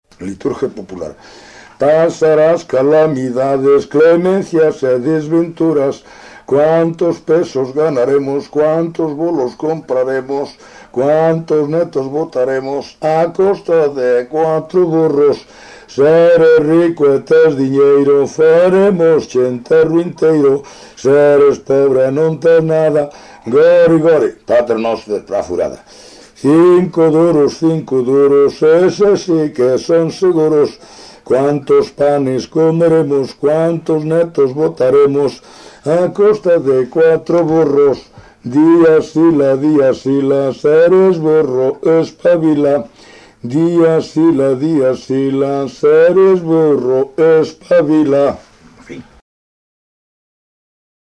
Palabras chave: igrexa parodia canto eclesiástico liturxia
Tipo de rexistro: Musical
Lugar de compilación: Fonsagrada, A - Fonfría (Santa María Madanela) - Fonfría
Soporte orixinal: Casete
Instrumentación: Voz
Instrumentos: Voz masculina